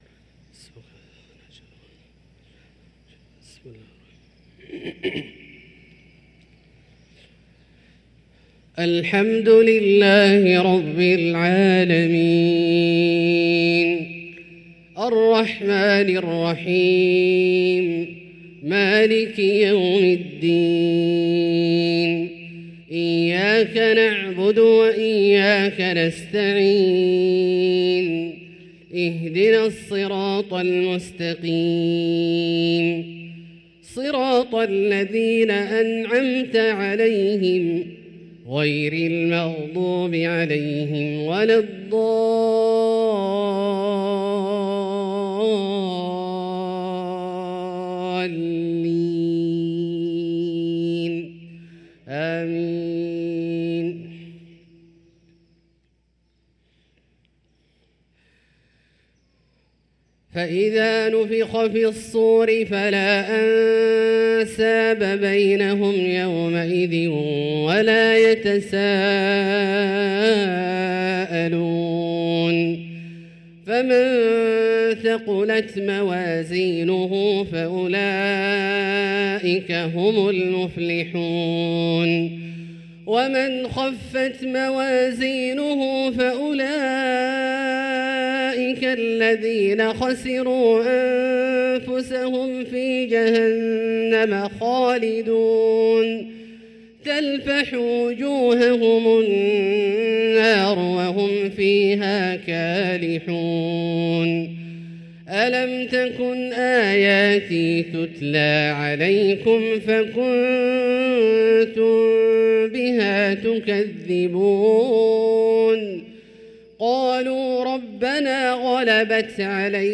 صلاة المغرب للقارئ عبدالله الجهني 11 جمادي الأول 1445 هـ
تِلَاوَات الْحَرَمَيْن .